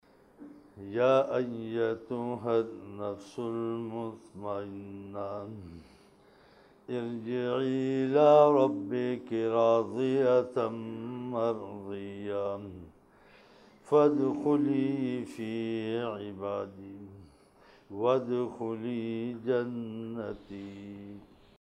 Tilawat - Surah Al-Fajr (Ayats 89:27-30)